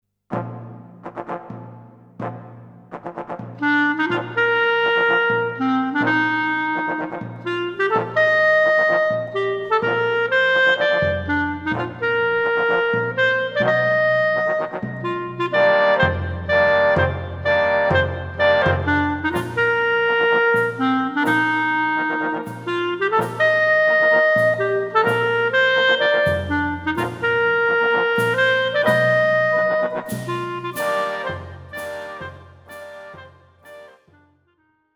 クラリネット+ピアノ